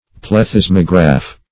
Plethysmograph \Pleth"ys*mo*graph\, n. [Gr. ? an enlargement +